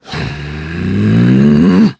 Clause_ice-Vox_Casting1_kr.wav